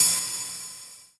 Golpe de platillo